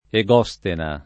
[ e g0S tena ]